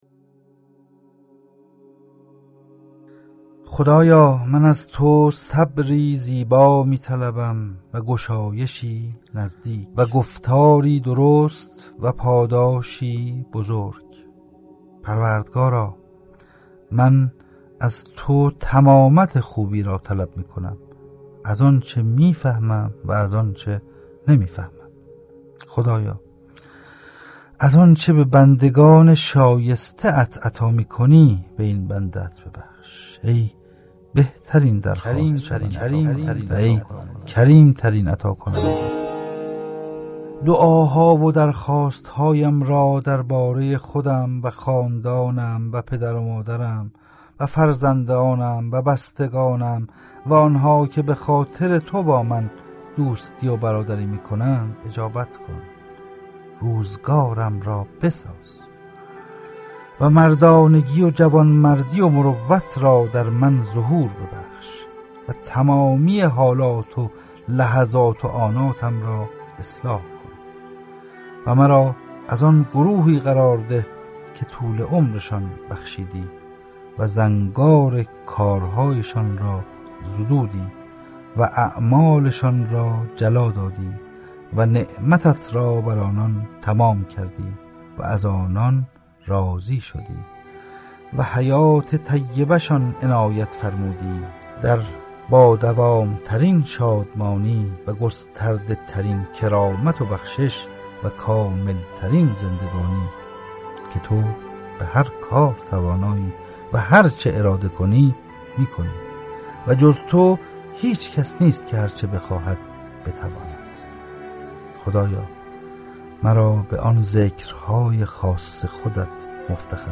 از دیگر مزیت‌های «شکوای سبز2» خوانش این اثر با صدای گیرای نویسنده و عرضه نسخه صوتی آن به روایت سیدمهدی شجاعی است که با استقبال چشمگیر مخاطب هم همراه بوده است.
کتاب صوتی این اثر نیز با روایت و صدای نویسنده تولید و منتشر شده و از طریق اپلیکیشن‌های فیدیبو، نوار، کتابراه و طاقچه قابل دریافت است.